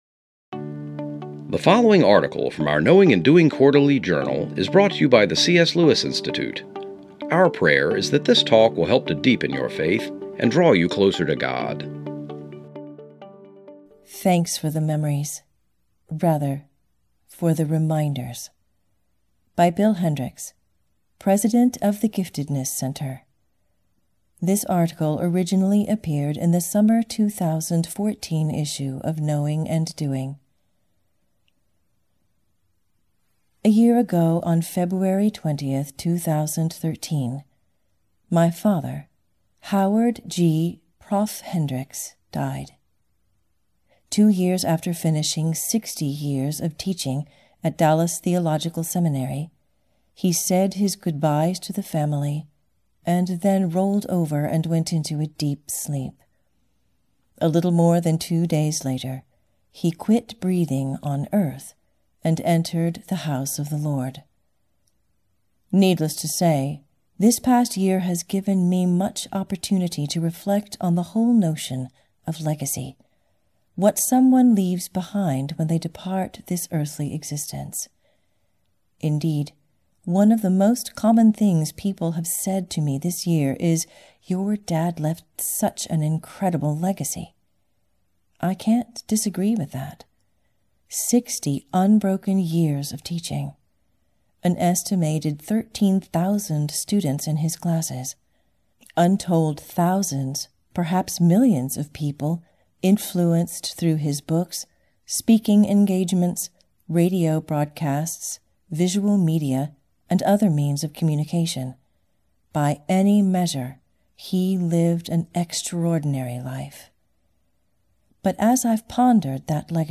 This episode is narrated